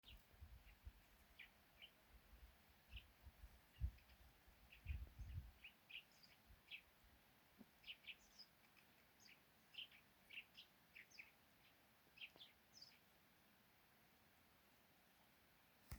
Lauku zvirbulis, Passer montanus
Administratīvā teritorijaVentspils novads